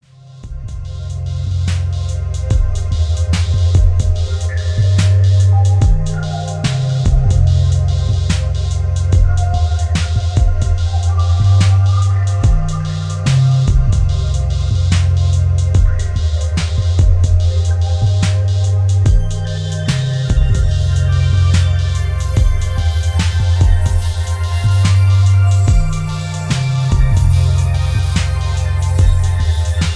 Tags: moody, thoughtfull, reflective